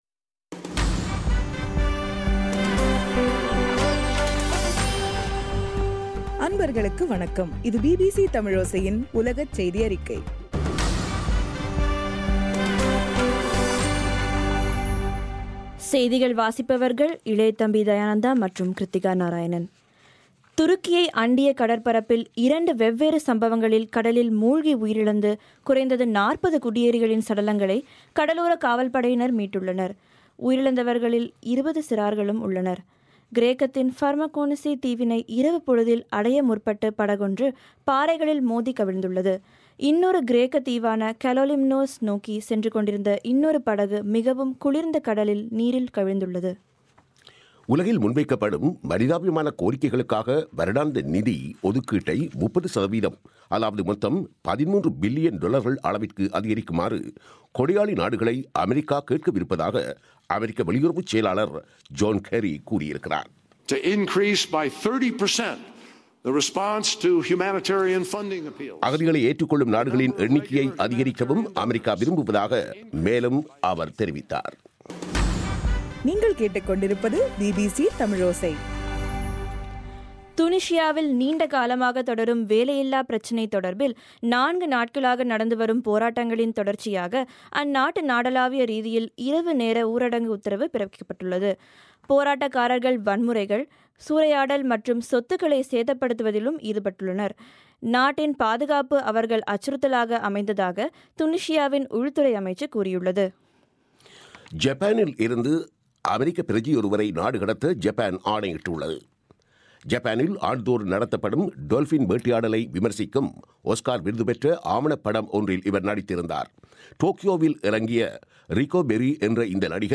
ஜனவரி 22, 2016 பிபிசி தமிழோசையின் உலகச் செய்திகள்